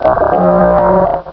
pokeemerald / sound / direct_sound_samples / cries / wailmer.aif